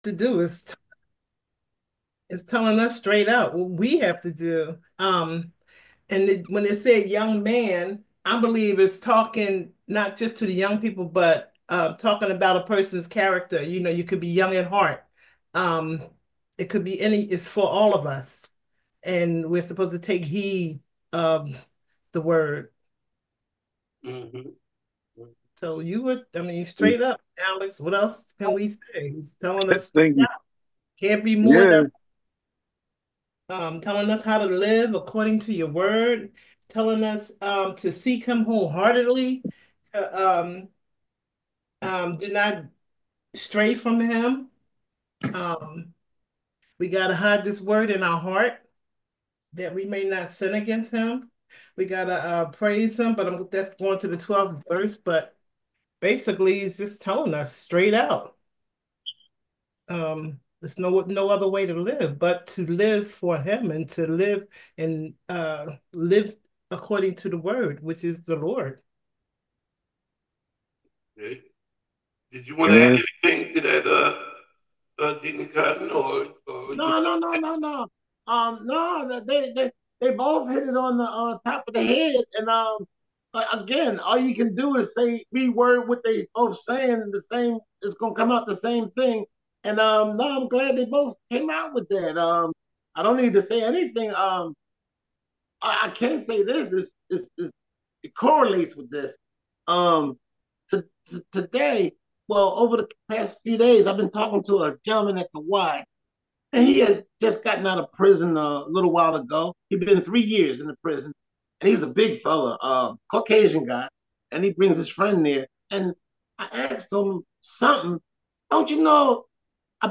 LOOKING BACK- St James Bible Study - St James Missionary Baptist Church